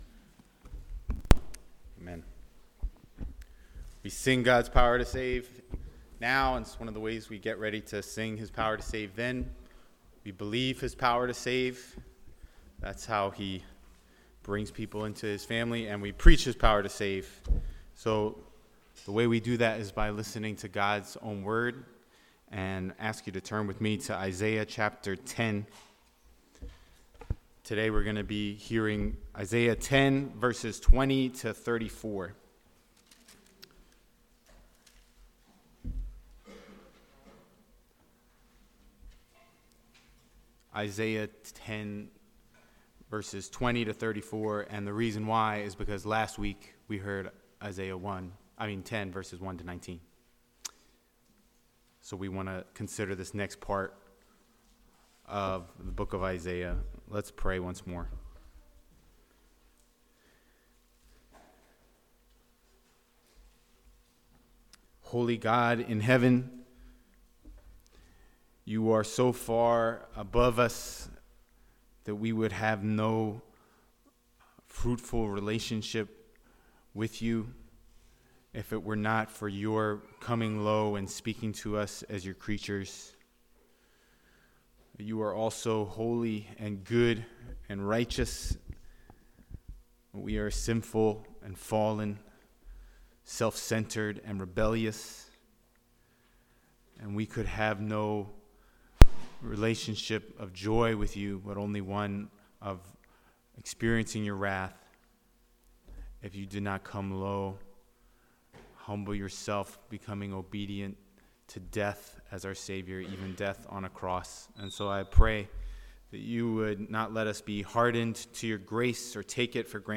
Passage: Isaiah 7 Service Type: Sunday Morning